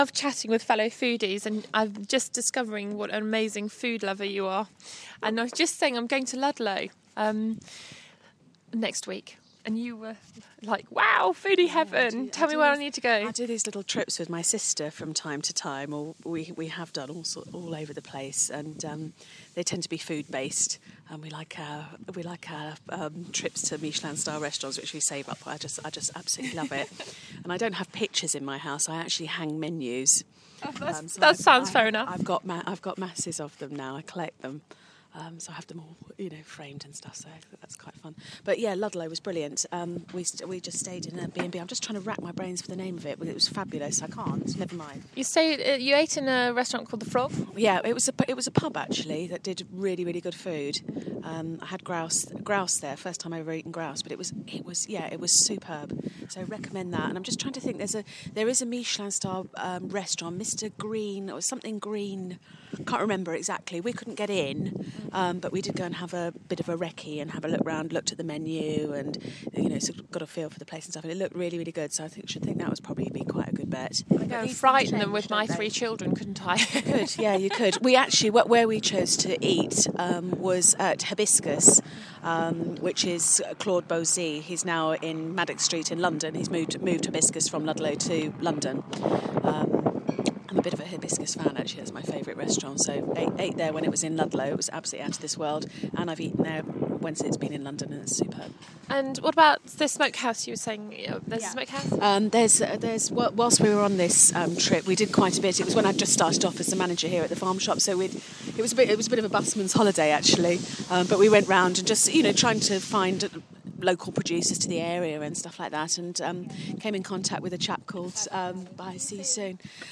Chatting to a fellow foodie about Ludlow